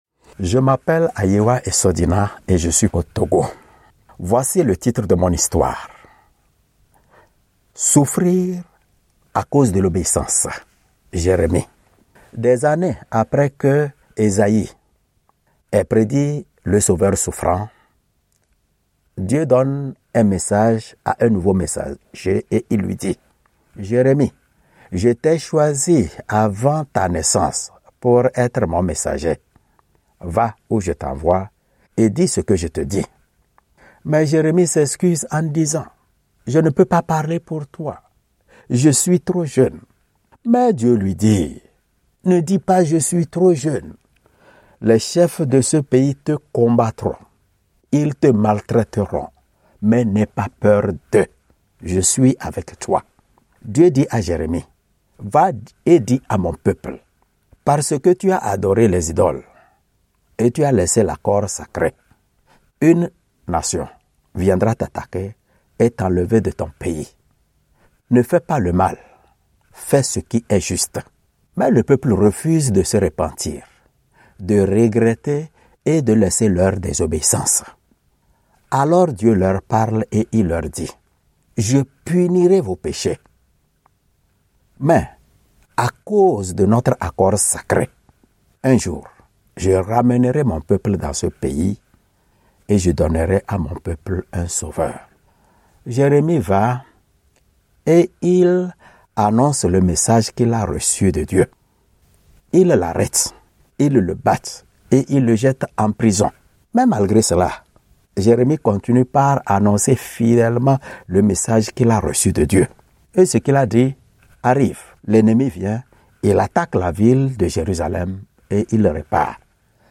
raconter l'histoire de la souffrance de Jérémie.